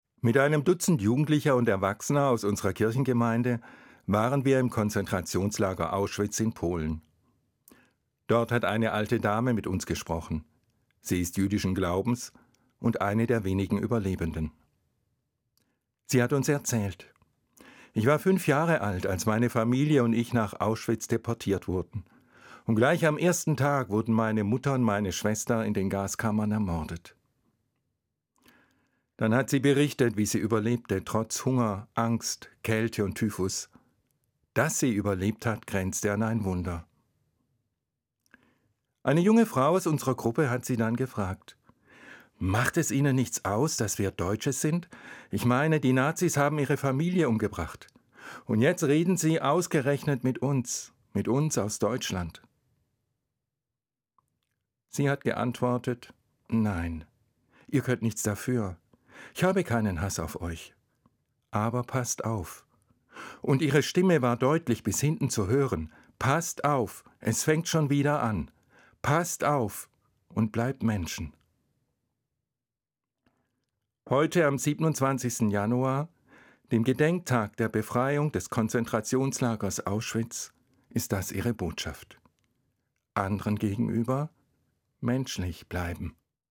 Evangelischer Pfarrer, Limburg